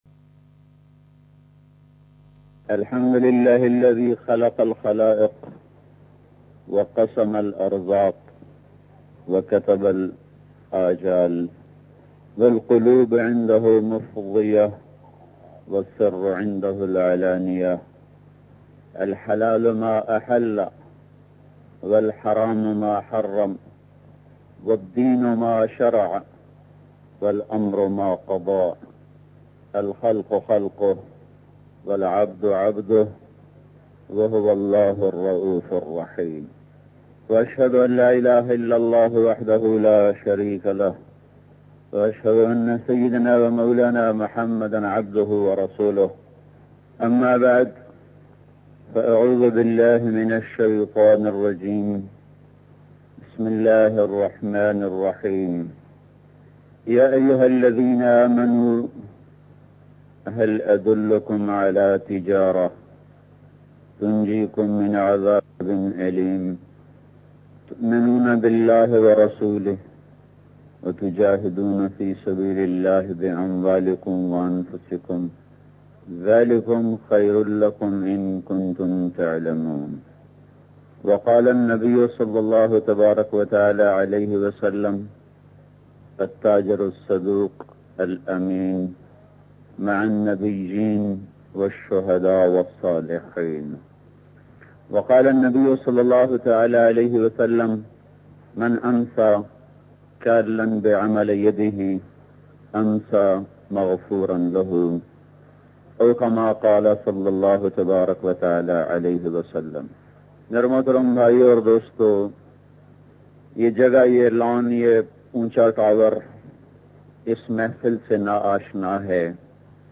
Tijarat ka islami tarika bayan mp3